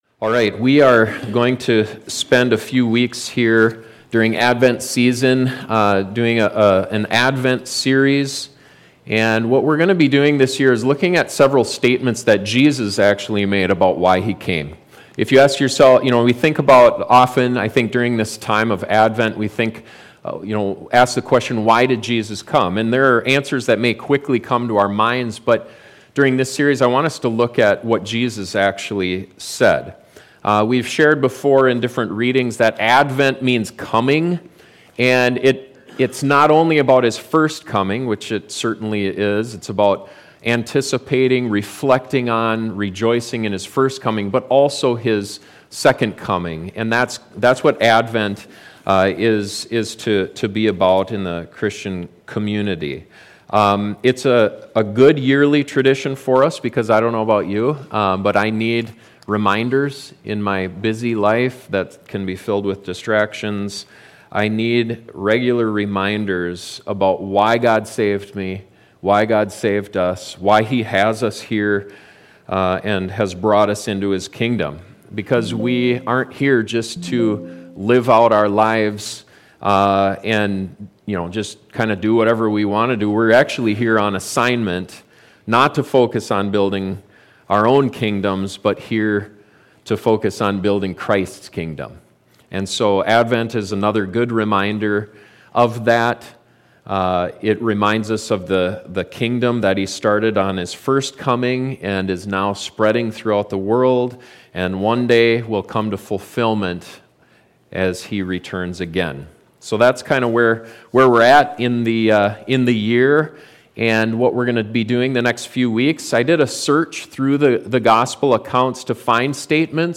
Jesus made several statements about why he came. This sermon looks at the first one, found near the beginning of the Sermon on the Mount.